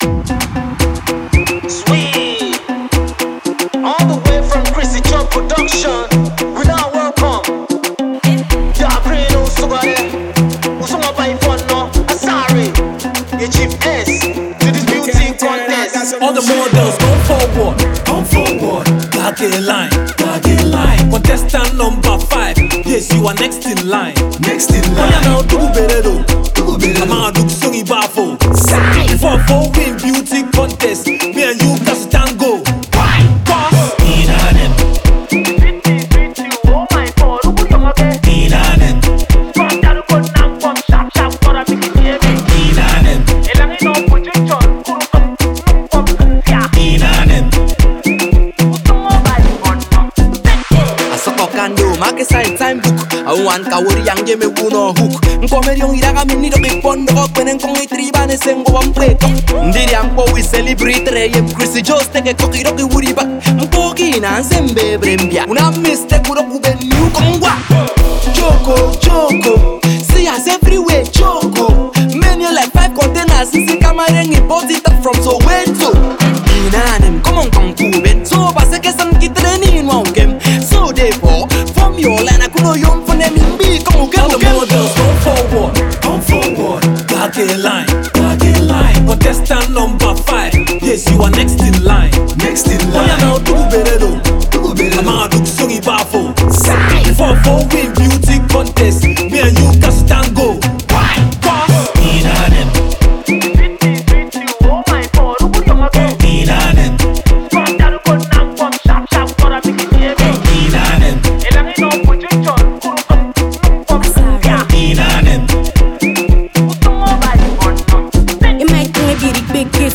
indigenous rappers